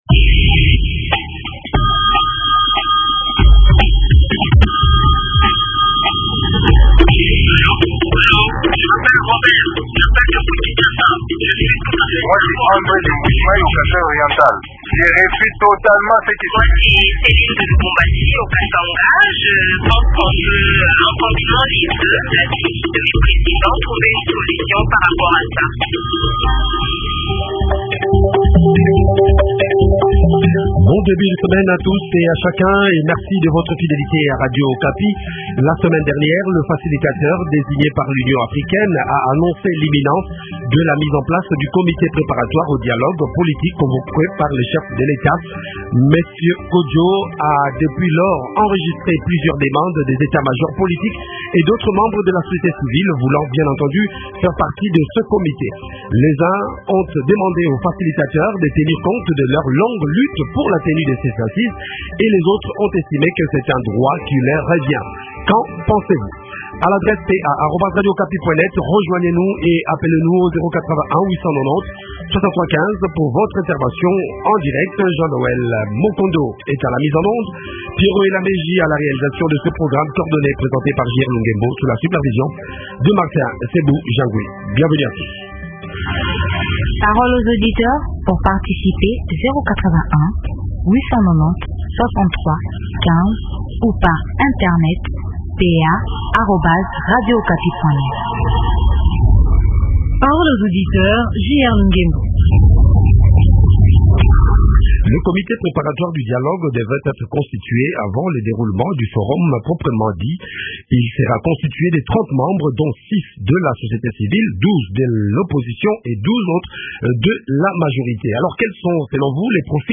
Invité : Steve Mbikayi, Président du parti travailliste du Congo, parti politique de l’opposition et porte parole de la nouvelle classe politique congolaise.